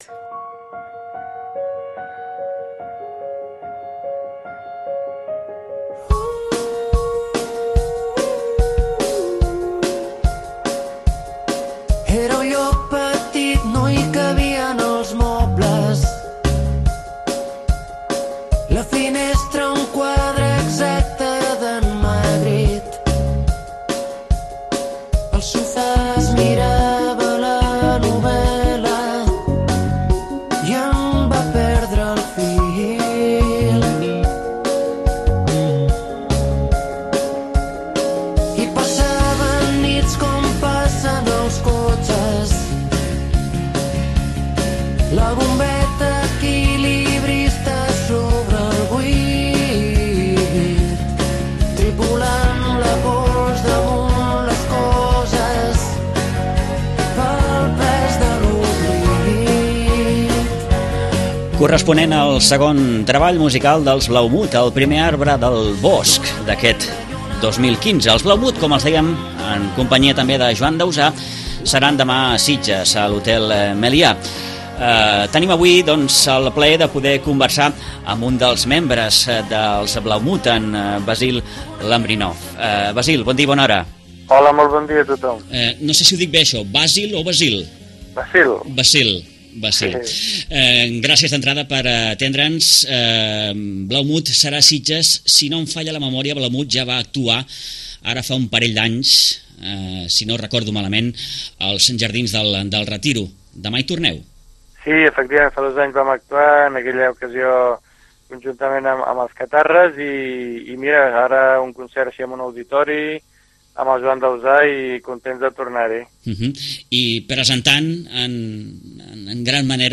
Demà Joan Dausà i els Blaumut seran en concert al Meliá Sitges, per a presentar els seus darrers treballs. Entrevistem